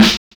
Snare set 2 005.wav